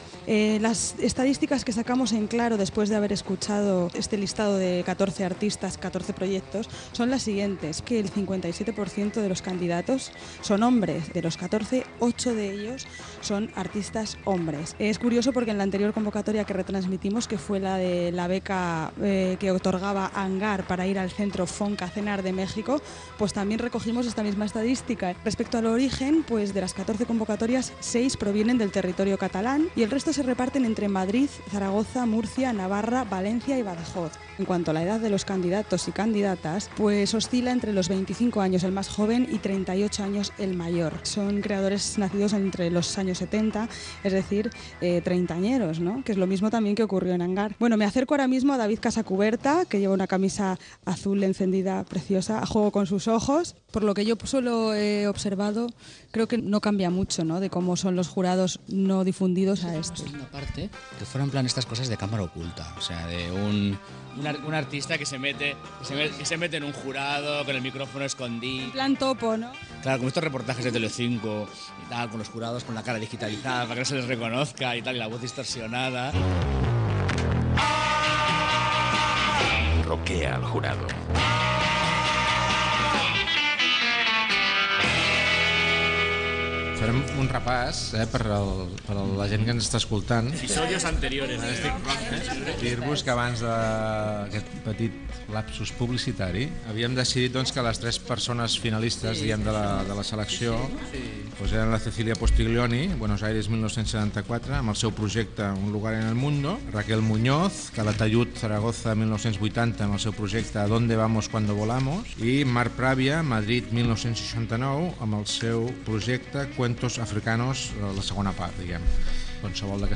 Careta del programa, inici de l'espai fet des de Can Xalant, Centre de Creació i Pensament Contemporani de Mataró, per atorgar el premi d'una estada a Dakar (Senegal) a un artista.